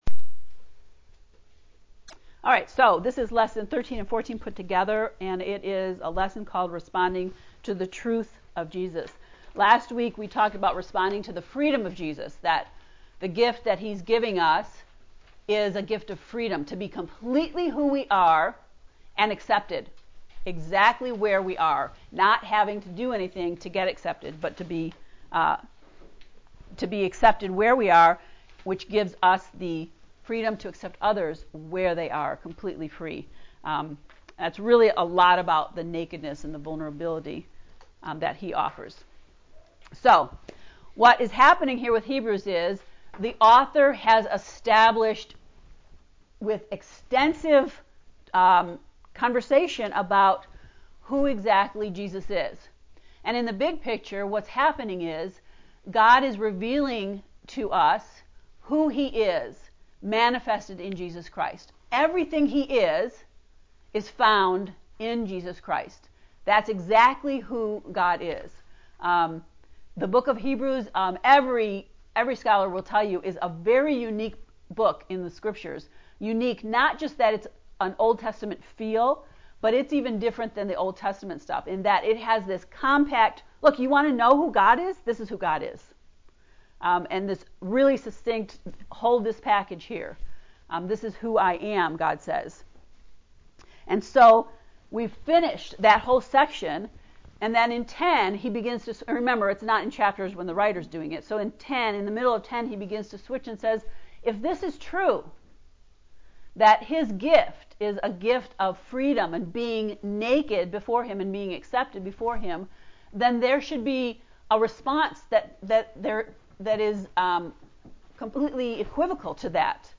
heb-ii-lecture-13-14.mp3